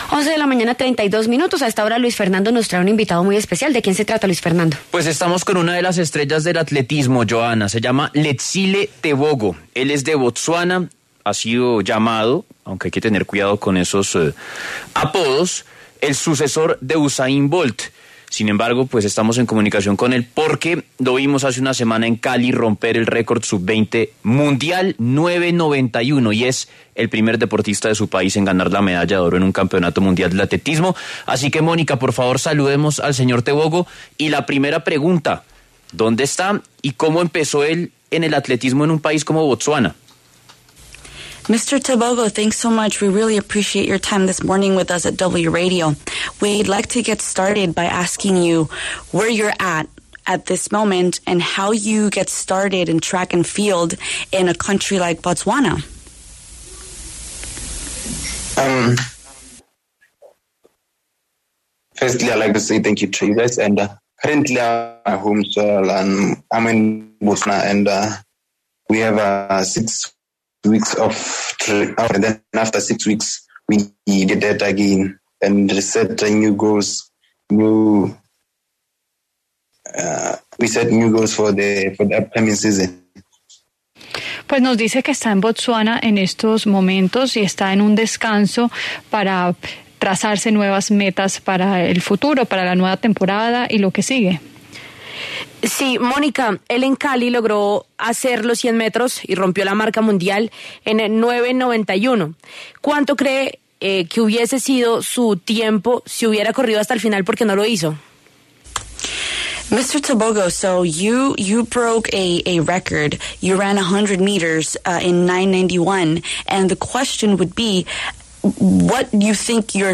El atleta nacido en Botsuana pasó por los micrófonos de La W para hablar sobre su carrera deportiva y su visita a Colombia.
En entrevista con La W, el atleta Letsile Tebogo, quien es el primer deportista de su país en ganar una medalla de oro en un campeonato mundial de atletismo, habló sobre sus inicios en este deporte.